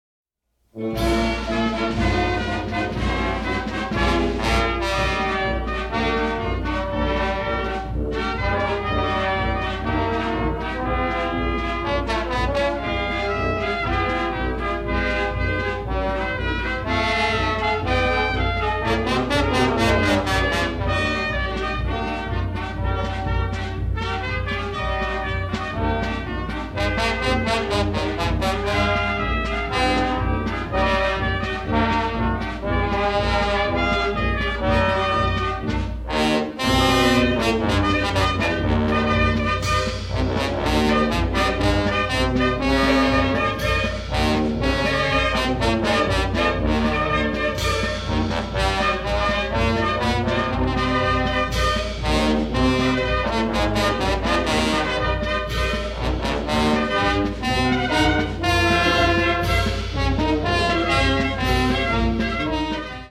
score is lush and full of kaleidoscopic orchestral color